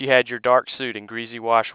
This is a time representation of a sentence.